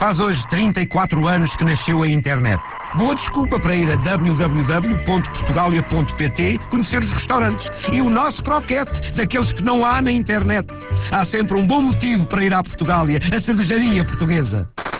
campanha (clique para ouvir o spot) em Rádio "Há sempre um bom motivo para ir à Portugália" com inserções na RFM e na Rádio Comercial entre 15 e 29 de Outubro foram investidos pela cervejaria 32 160 € em 88 inserções.